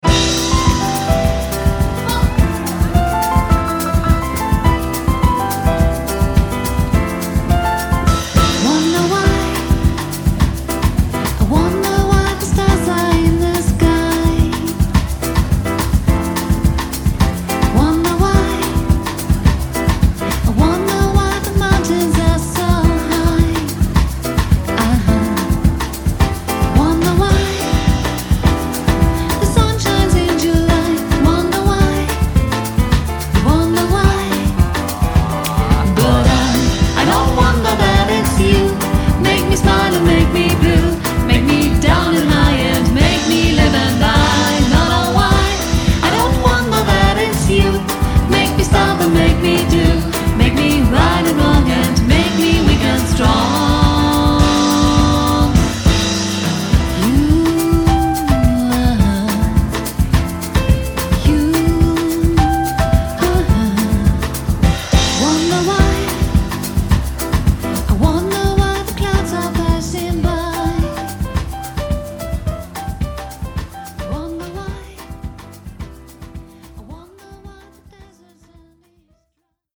jazz can dance